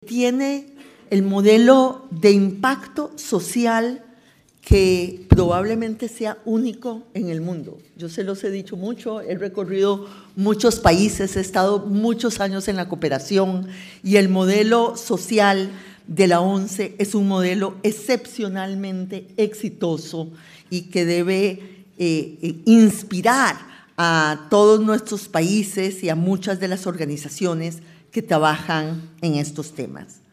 En una conferencia organizada por Fórum Europa pasa revista a la actualidad social y reclama "respeto y reconocimiento" para las organizaciones de la sociedad civil